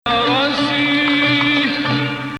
Sikah 8